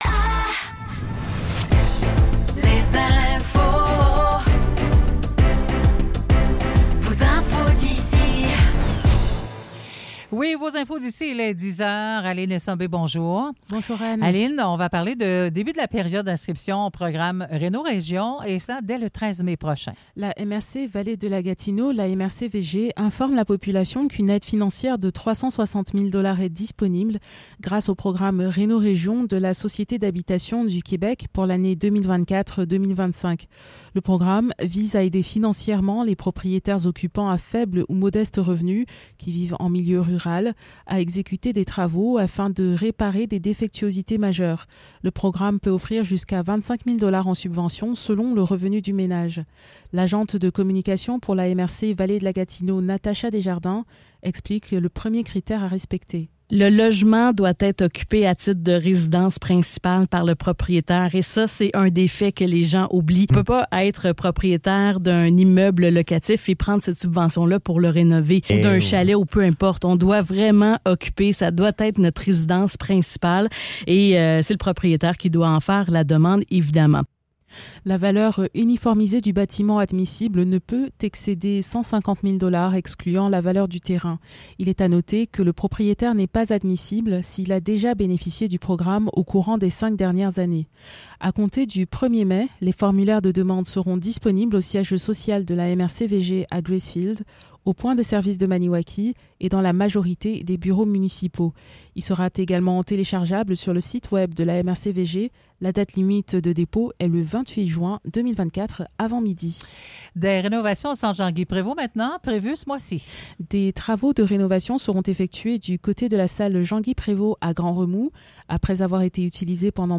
Nouvelles locales - 7 mai 2024 - 10 h